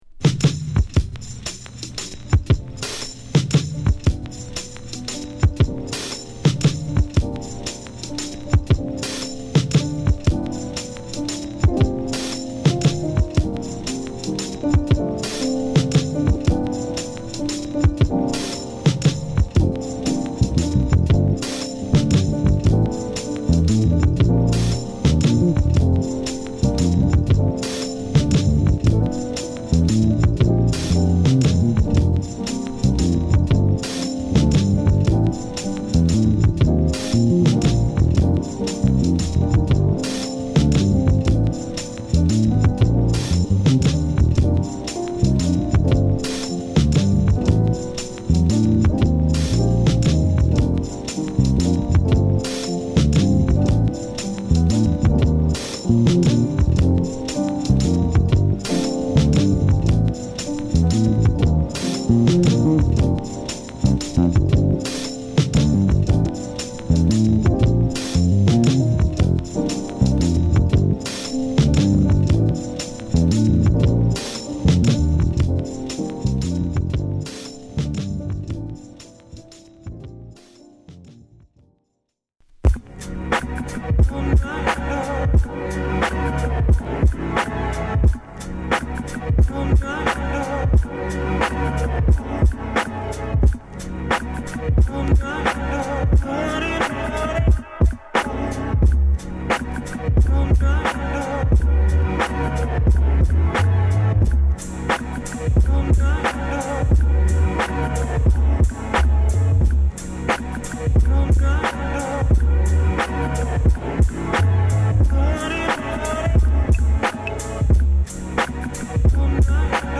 サンプリング、コラージュで艶やかに彩られ、シットリとした雰囲気が抜群に気持ち良いビートアルバム。
ファットなビートに哀愁アコギの相性は最高。
都会の中に隠れているケイブに入り込んだかのような空気感。